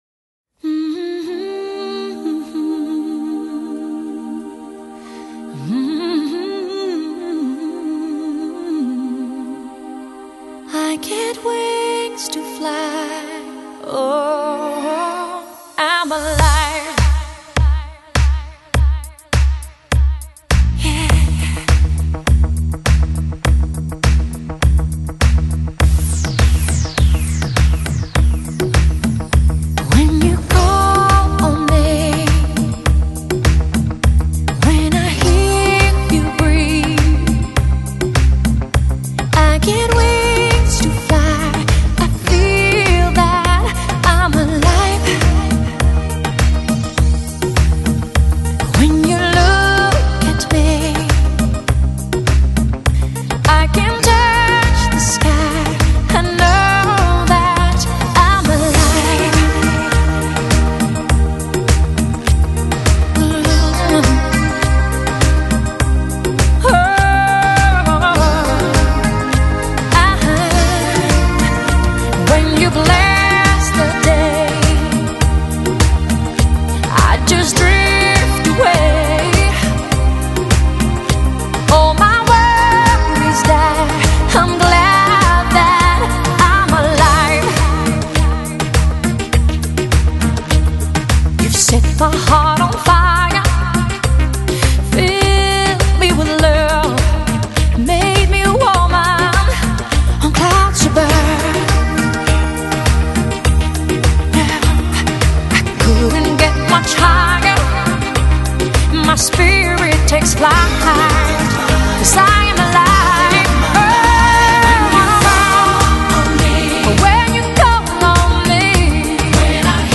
、情感丰沛的歌声中，感受到旺盛的生命能量，热烈地传送到世界每个角落；节奏轻快带来好心情的